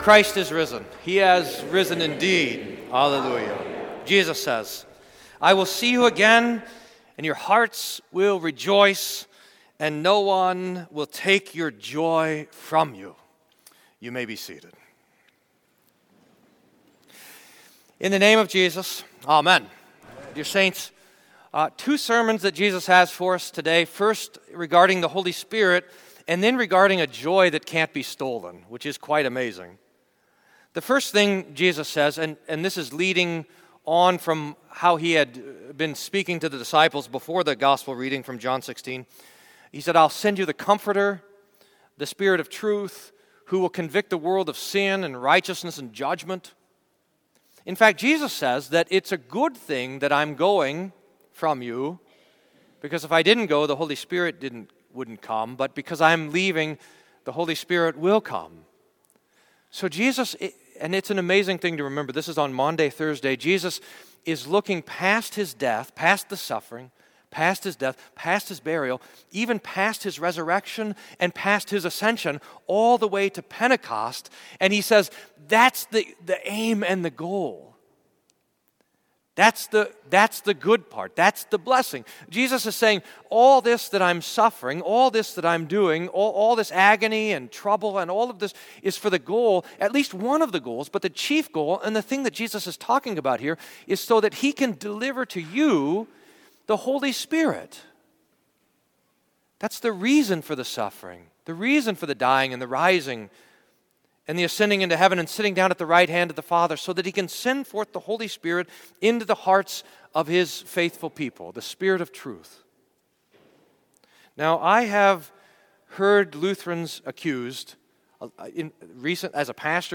Sermon for Fifth Sunday of Easter